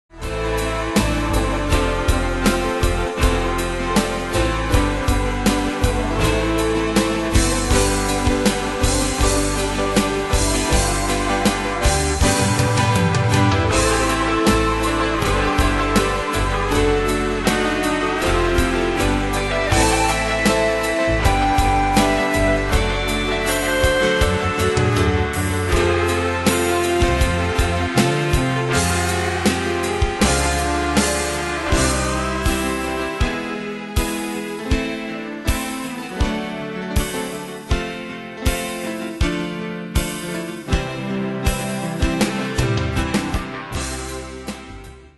Style: PopFranco Ane/Year: 2008 Tempo: 80 Durée/Time: 3.37
Danse/Dance: Rock Cat Id.
Pro Backing Tracks